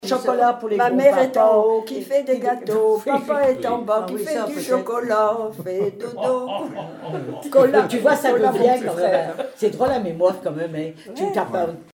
Genre brève
Enquête Douarnenez en chansons
Pièce musicale inédite